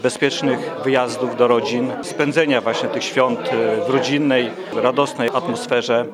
To już piękna świąteczna tradycja – wigilia w Radiu 5 Ełk.